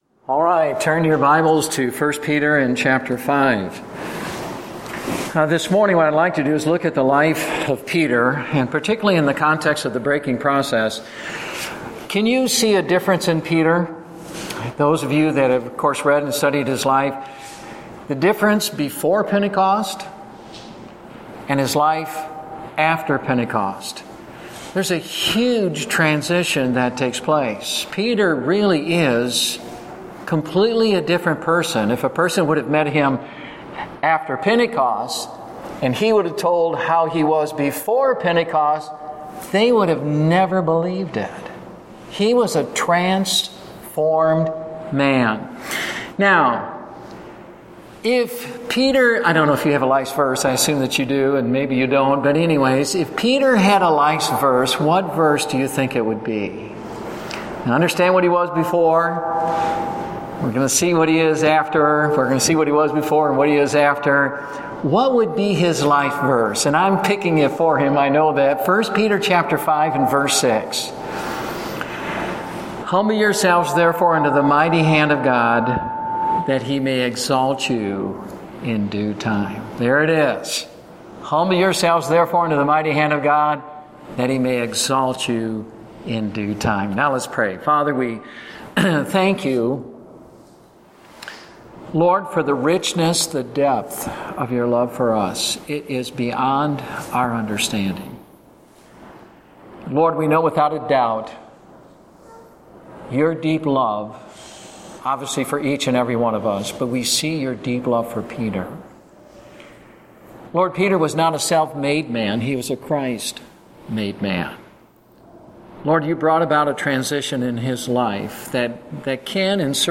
Date: August 22, 2014 (Family Camp)